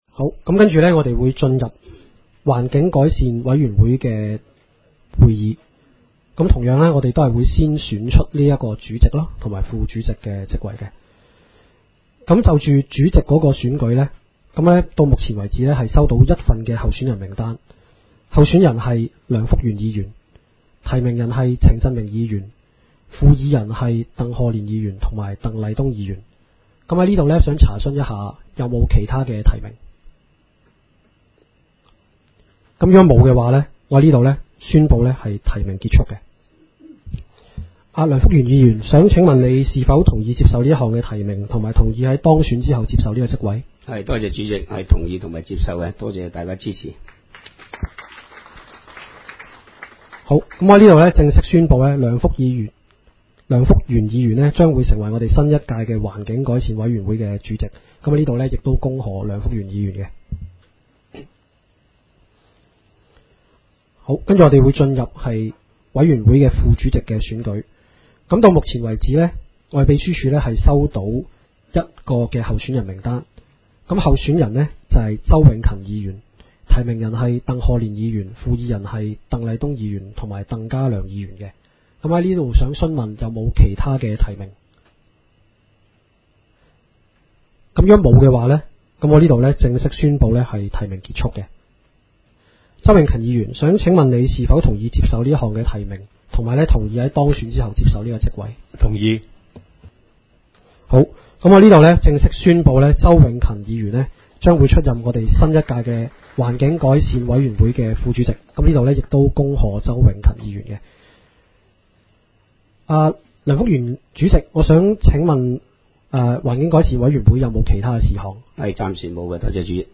委员会会议的录音记录
地点: 元朗桥乐坊2号元朗政府合署十三楼会议厅